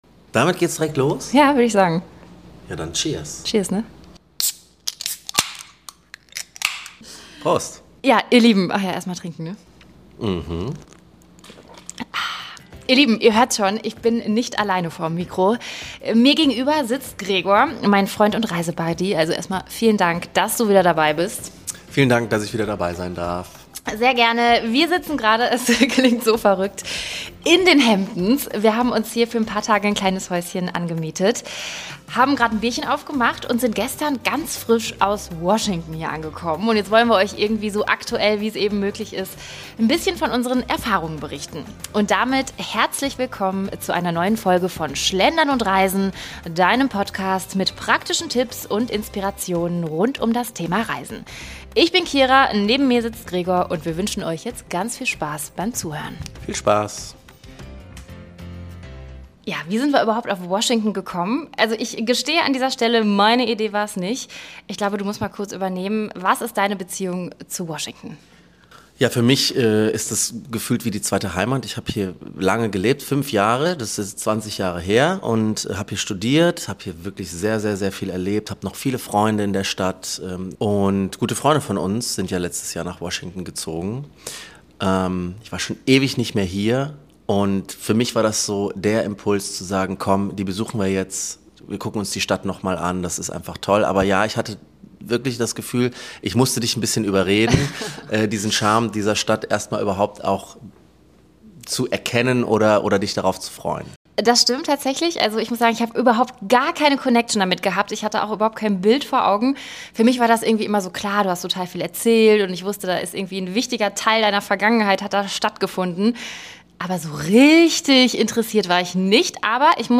am Küchentisch